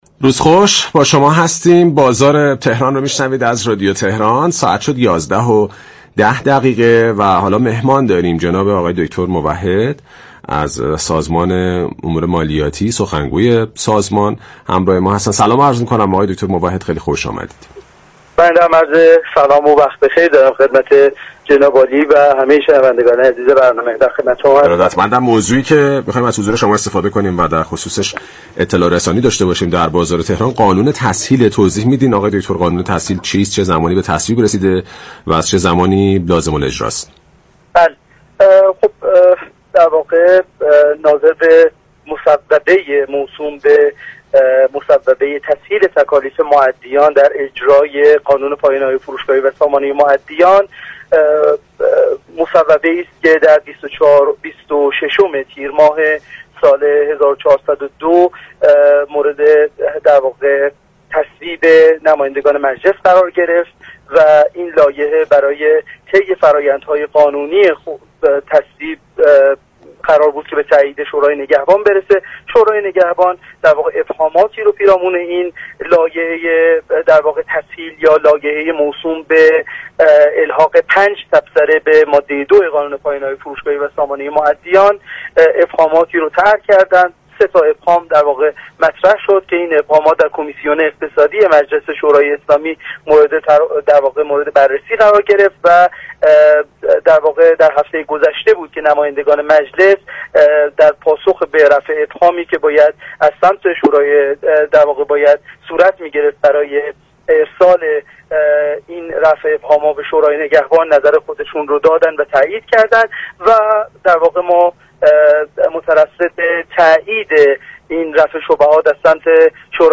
در گفت و گو با «بازار تهران» درخصوص قانون سامانه مودیان مالیاتی و پایانه‌های فروشگاهی